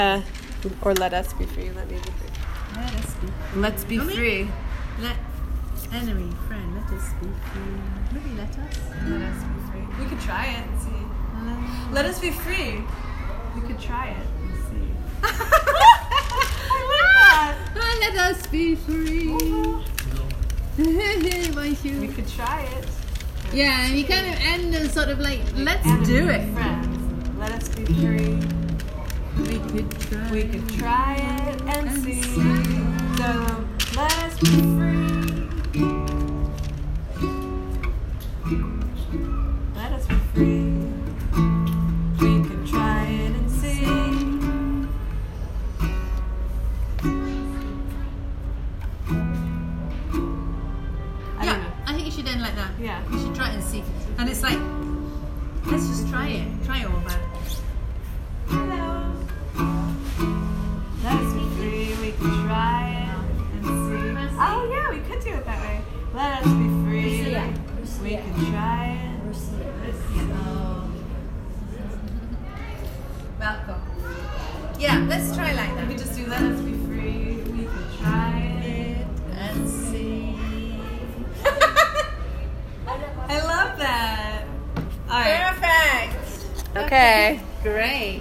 These are recordings from the center that show the process of writing the song:
Spoken word/bridge runthrough